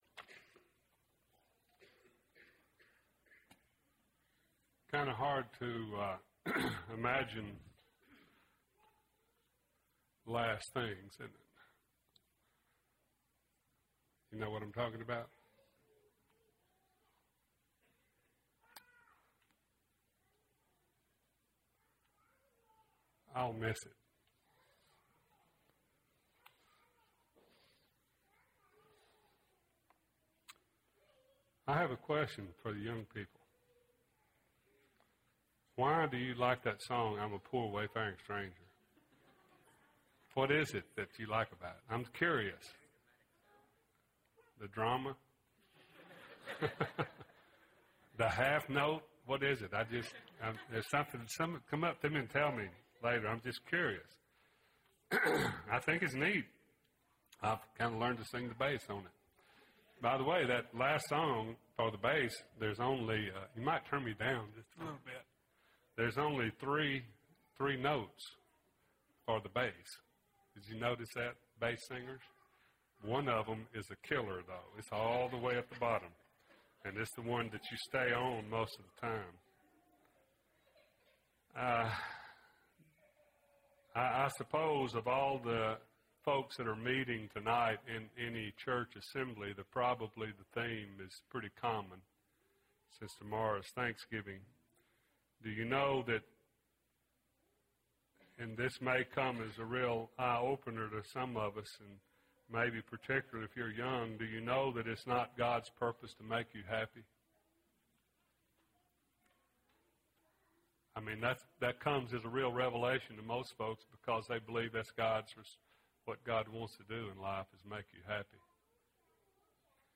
A Study of Selected Psalms (10 of 10) – Bible Lesson Recording